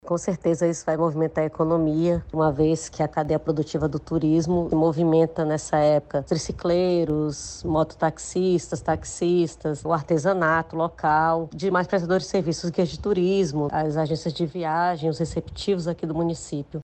Com isso, diversas atividades econômicas recebem alta demanda de serviços, destaca a secretária de Cultura e Turismo do Município, Karla Viana.
SONORA-1-TEMPORADA-CRUZEIROS-PARINTINS-.mp3